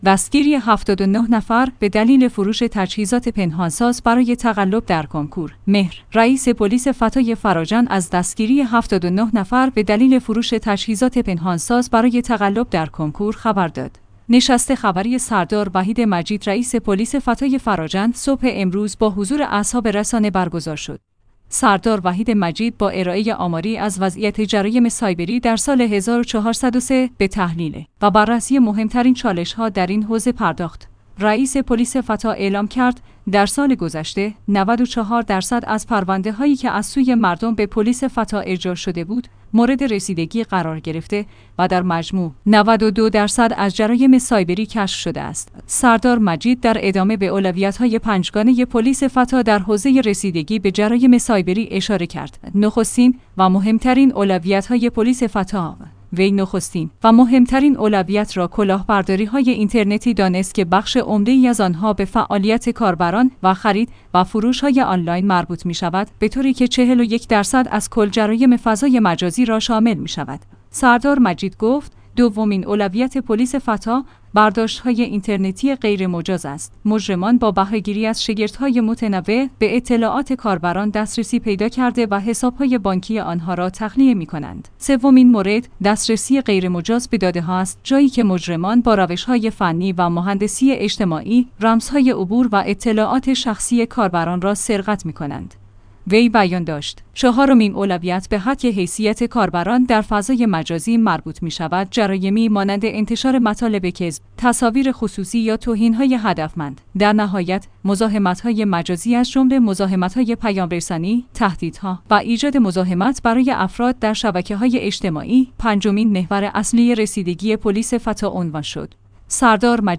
نشست خبری سردار وحید مجید رئیس پلیس فتا فراجا صبح امروز با حضور اصحاب رسانه برگزار شد.